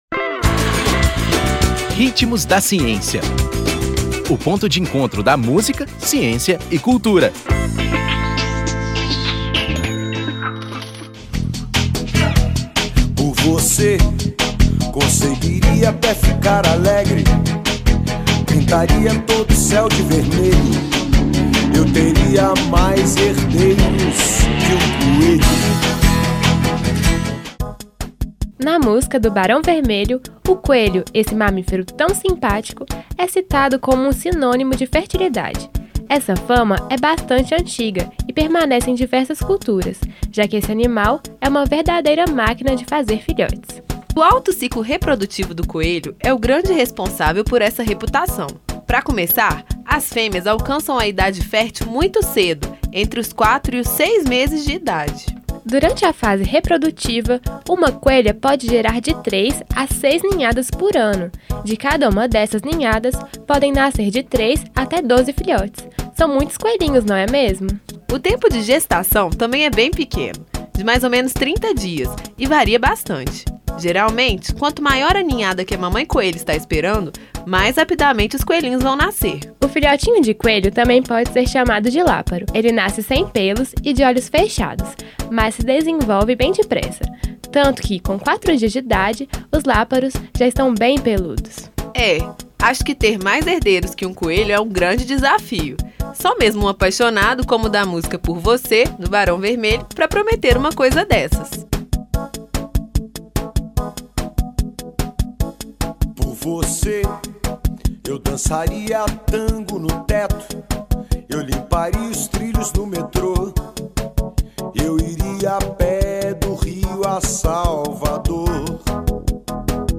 Vozes
Nome da música: Por você
Intérprete: Barão Vermelho